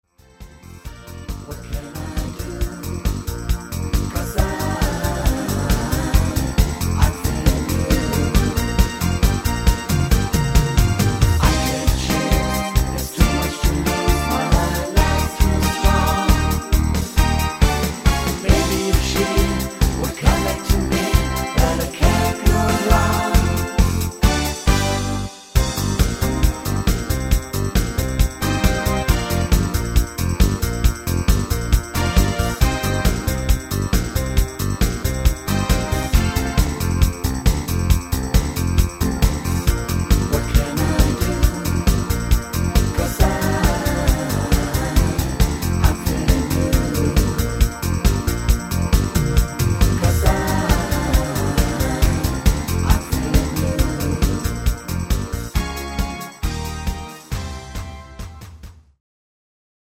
Rhythmus  8 Beat
Art  Englisch, Medleys, Oldies